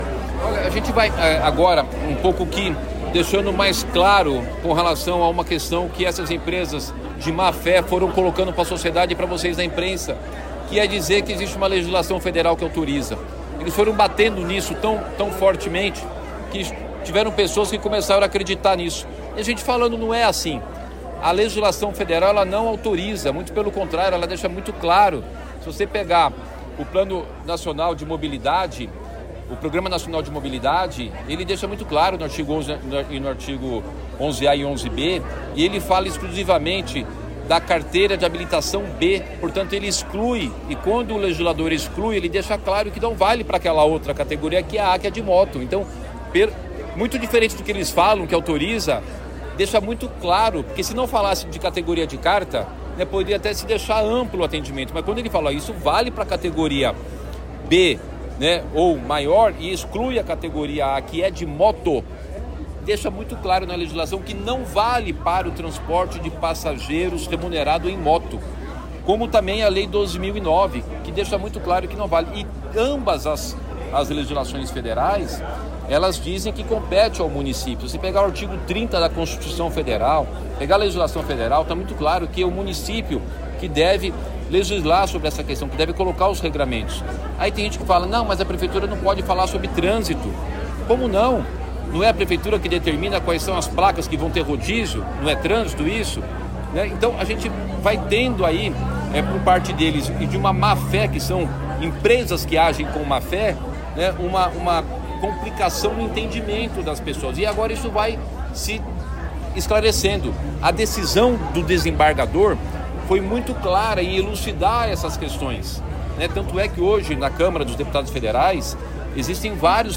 OUÇA TODA A DECLARAÇÃO DO PREFEITO RICARDO NUNES NESTA TERÇA-FEIRA, 28 DE JANEIRO DE 2025:
TRECHO-NUNES-99-UBER.mp3